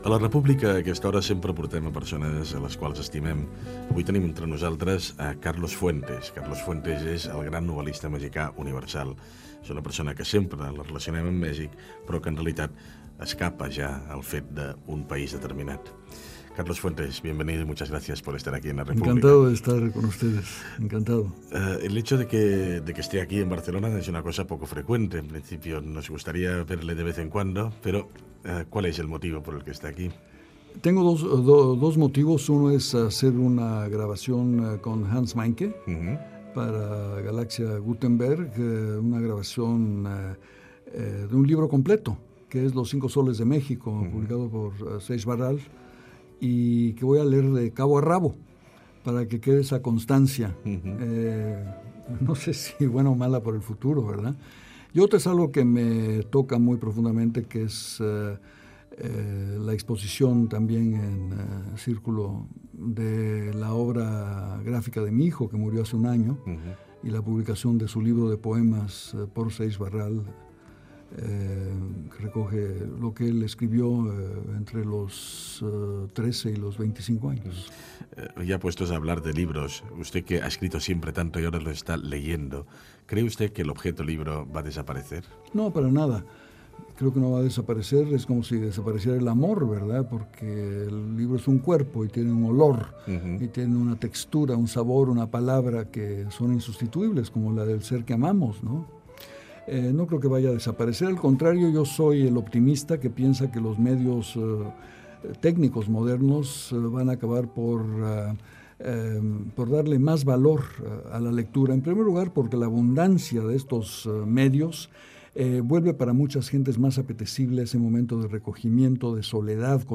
Perfil biogràfinc i fragment d'una entrevista a l'escriptor mexicà Carlos Fuentes.
Info-entreteniment
Fragment extret de l'arxiu sonor de COM Ràdio.